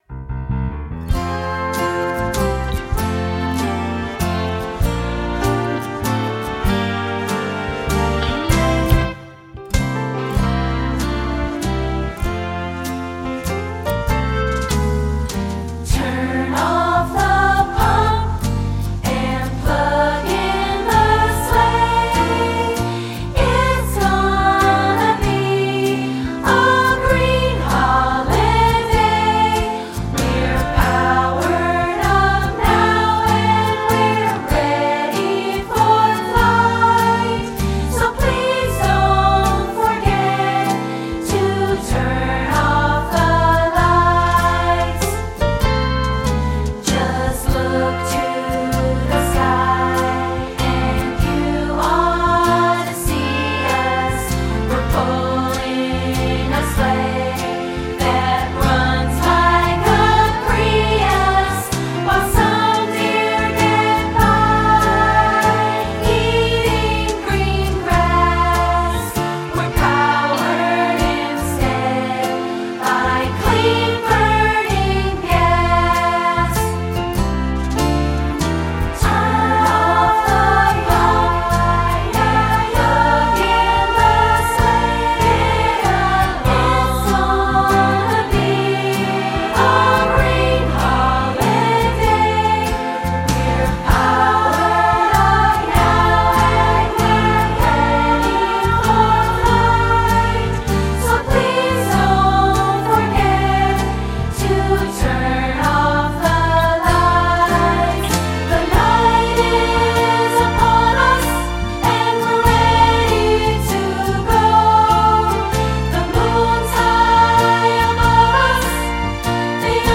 Mp3 with singing and dialogue: